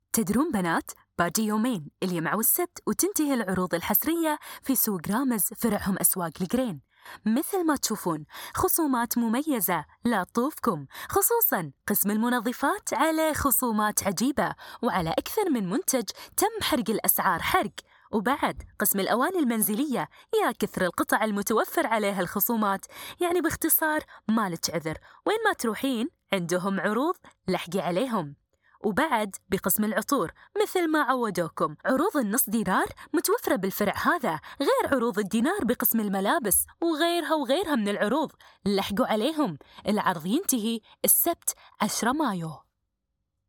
قمت بتسجيل هذا الاعلان لصالح سوق مركزي في دولة الكويت ، وتم تسجيل الصوت بمايكروغون احترافي ، وتم الحرص على تسجيل الصوت وخروجة في افضل صورة ، تم تسجيل الصوت وتحريرية تسليمه فقط خلال ساعة واحدة
اعلان لصالح سوق مركزي في الكويت